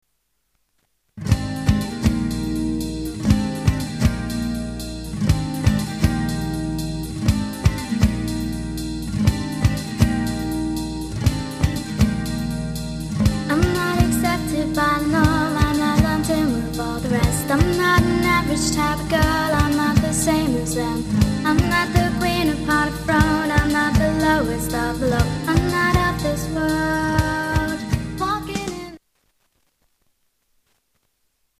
STYLE: Rock
Delay is used heavily and to good effect